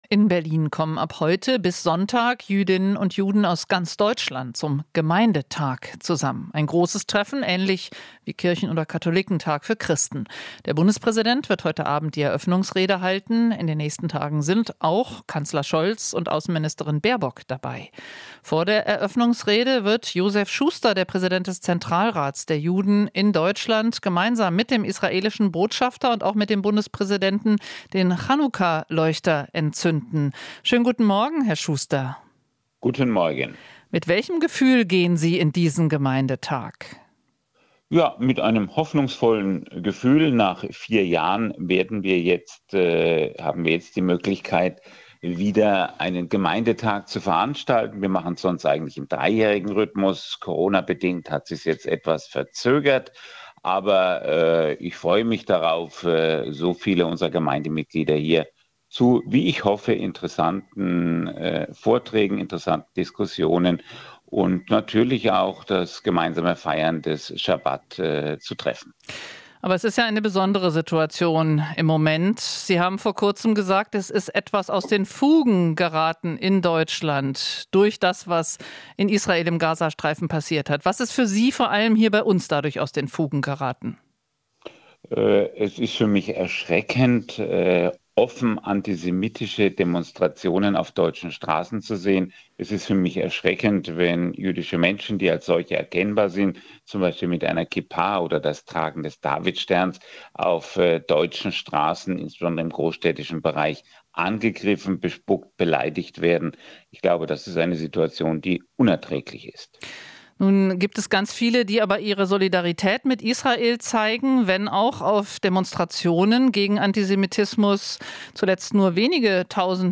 Interview - Zentralrat der Juden: Erwarten klare Anerkennung des Existenzrechts Israels